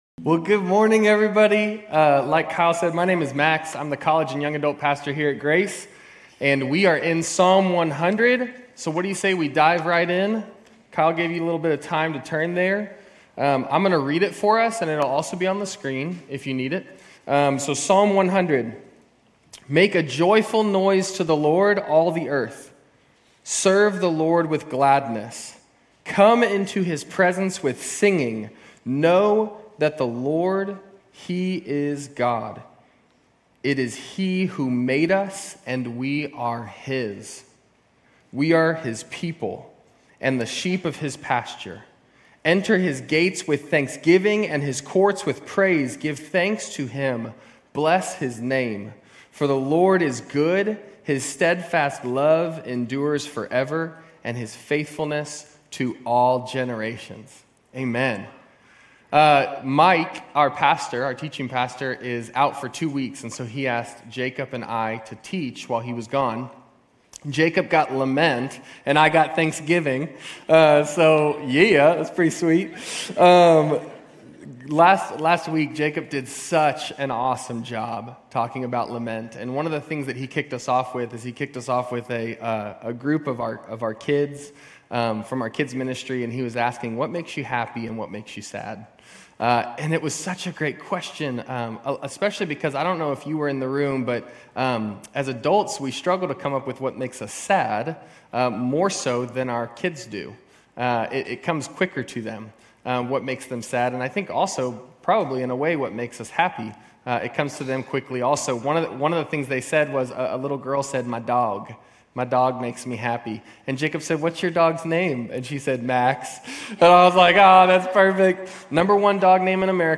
Grace Community Church University Blvd Campus Sermons Psalm 100 - Thanksgiving Jul 07 2024 | 00:32:09 1x 00:00 / 00:32:09 Subscribe Share RSS Feed Share Link Embed